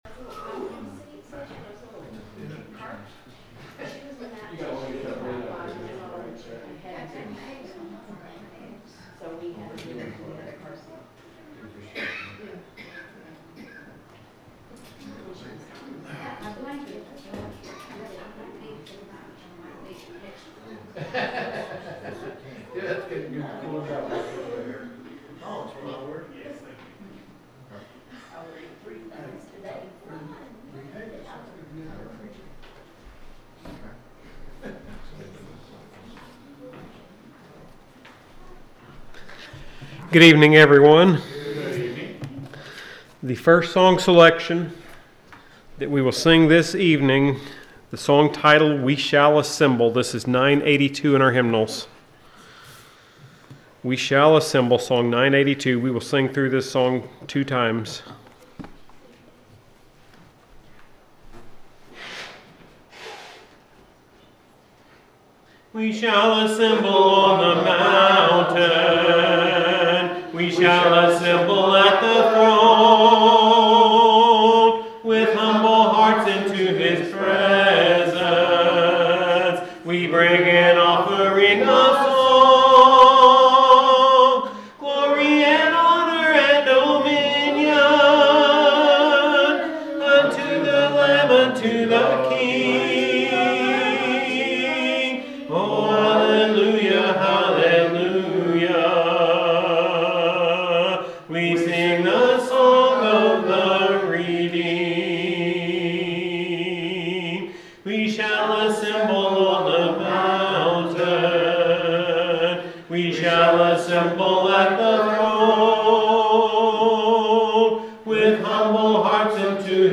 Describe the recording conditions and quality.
The sermon is from our live stream on 10/19/2025